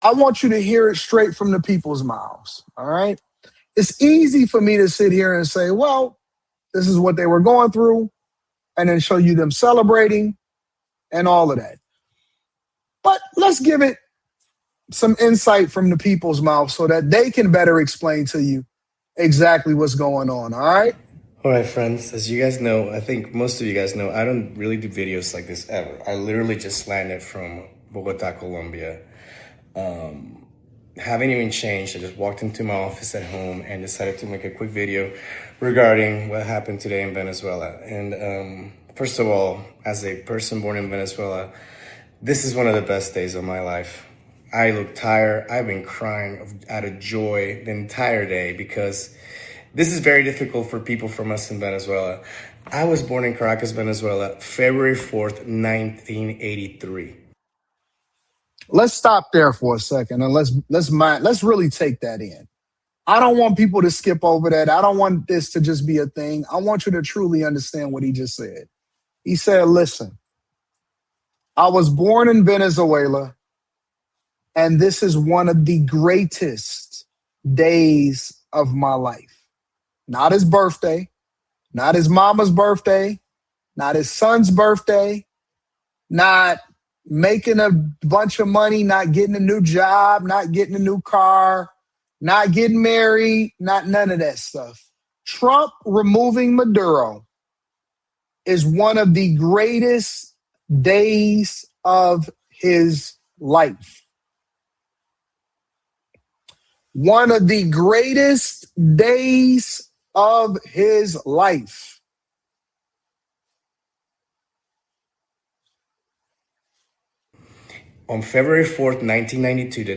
A Venezuelan shares his happiness about positive political changes in his homeland, calling it one of the best days of his life. He talks about the struggles he faced growing up and urges people to understand the real situation. He encourages listening to Venezuelans instead of relying on social media and highlights their hope to rebuild their country.